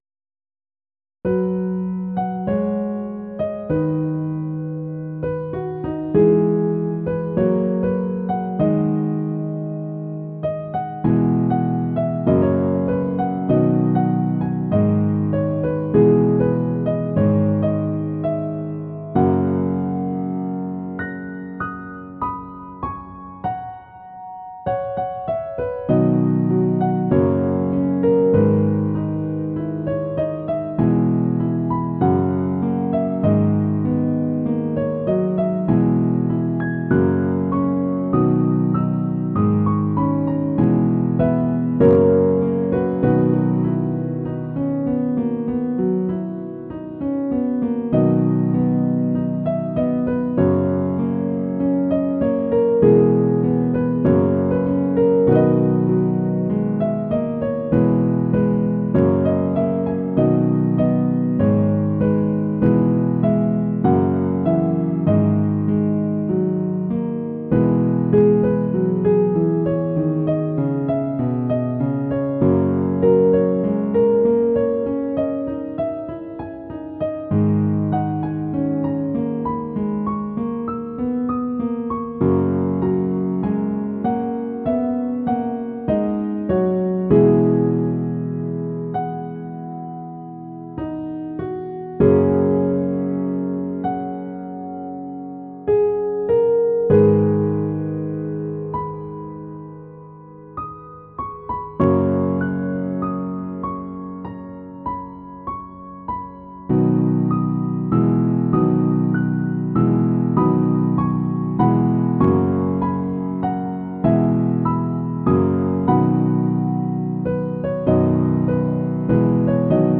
Ezen okok hatására kezdtem el saját zongoradallamokat írni, amikből ide csatolok egy párat